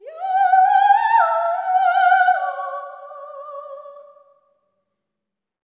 OPERATIC05-R.wav